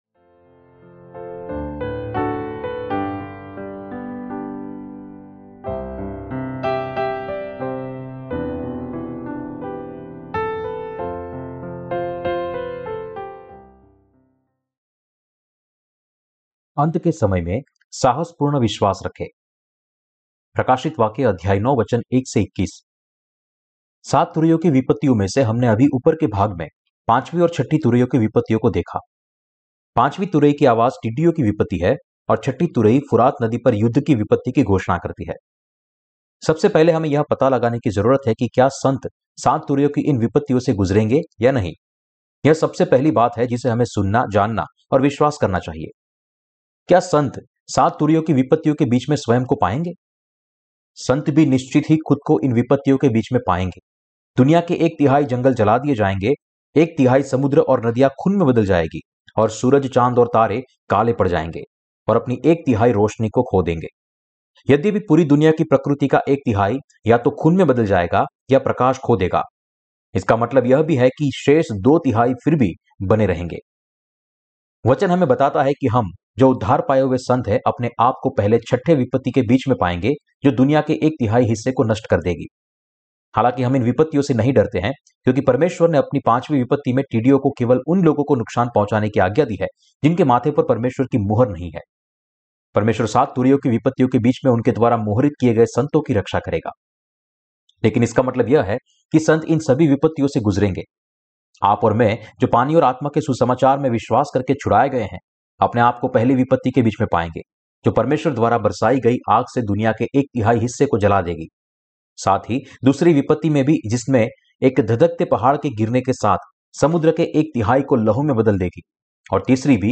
प्रकाशितवाक्य की किताब पर टिप्पणी और उपदेश - क्या मसीह विरोधी, शहादत, रेप्चर और हजार साल के राज्य का समय नज़दीक है?